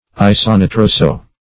Search Result for " isonitroso-" : The Collaborative International Dictionary of English v.0.48: Isonitroso- \I`so*ni*tro"so-\ [Iso- + nitroso-.]